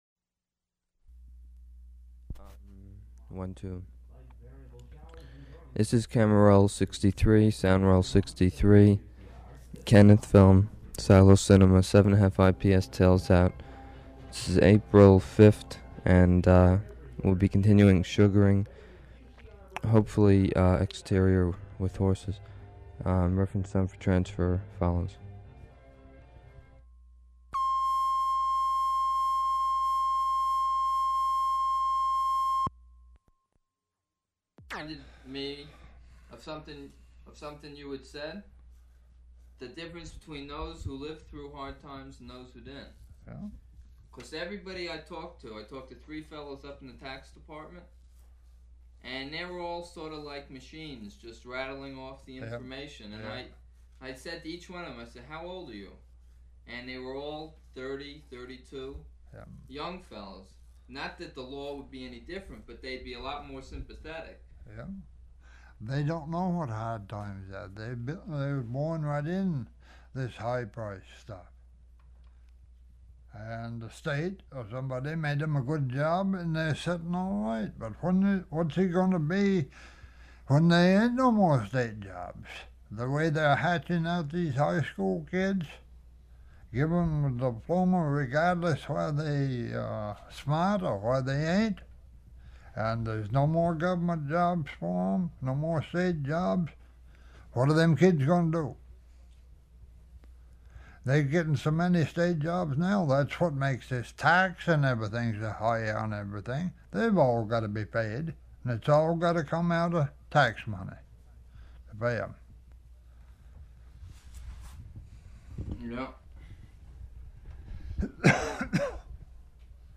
Description Sound Recording Content Interview